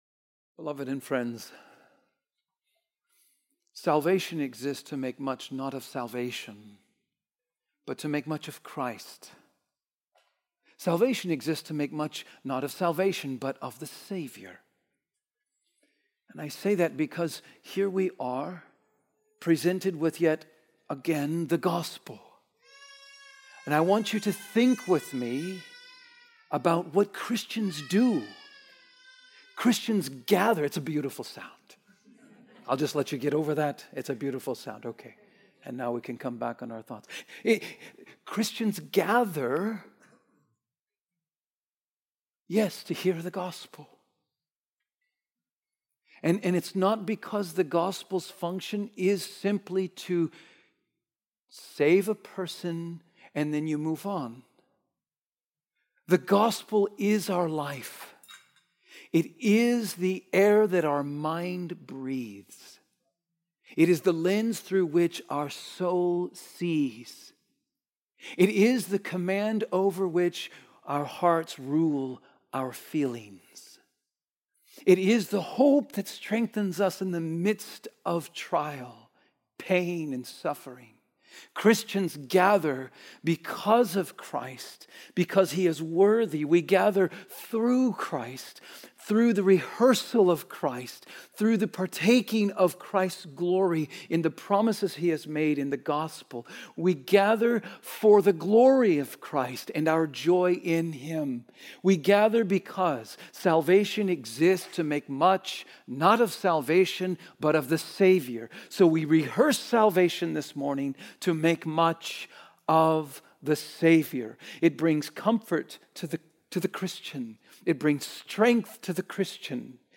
Sermons - Trinity Bible Church